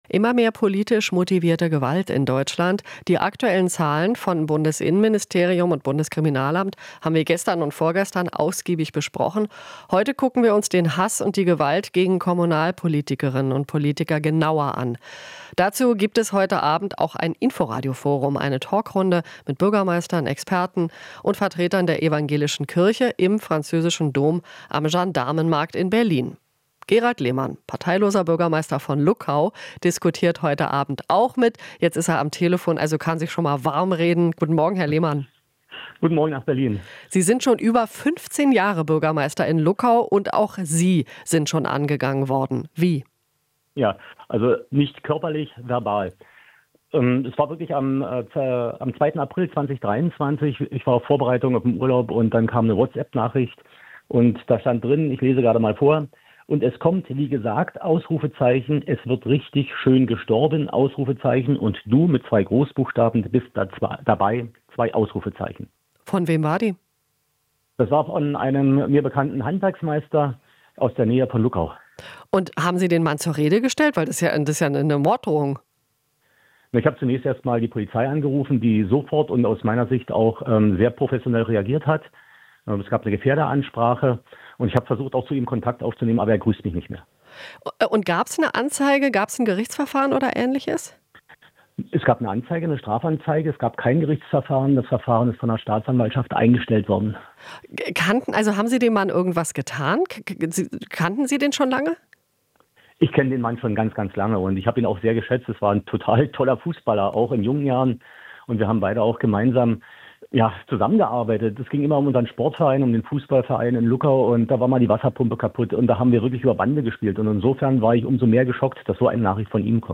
Interview - Luckaus Bürgermeister: Trotz Anfeindungen den Dialog suchen